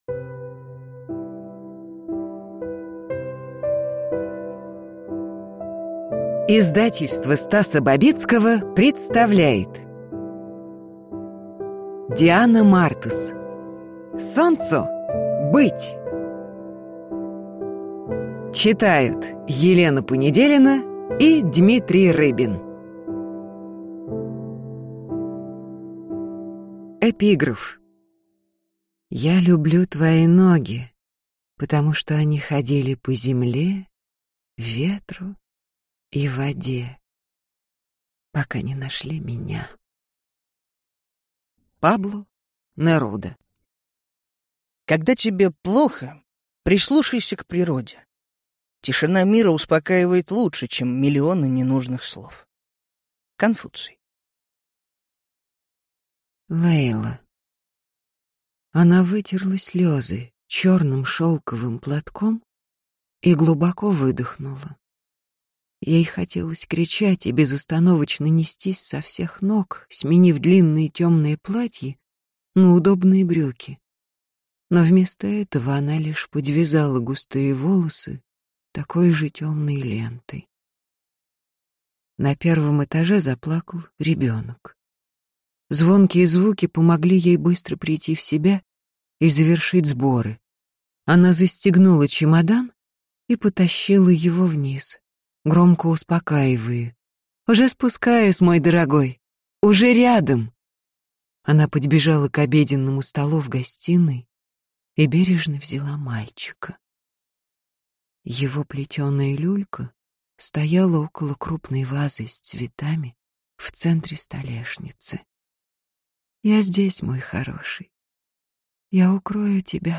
Аудиокнига Солнцу быть!
Прослушать и бесплатно скачать фрагмент аудиокниги